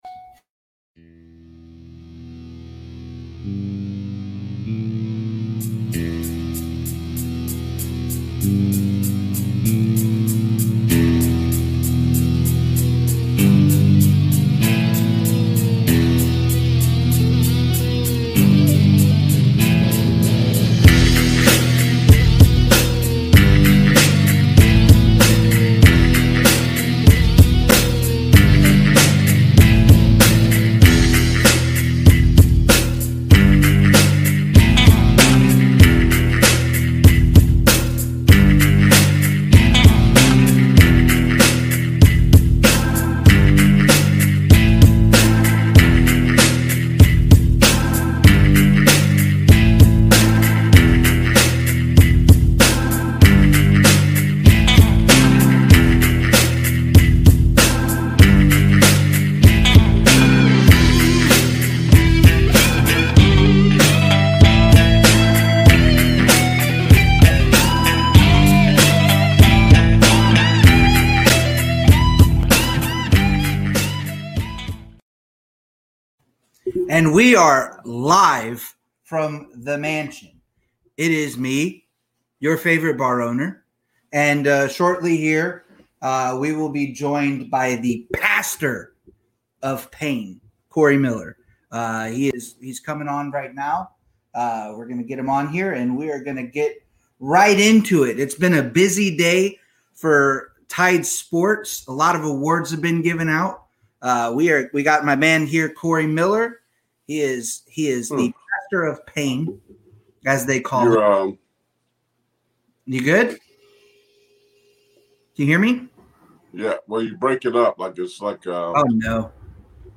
LIVE From the Mansion!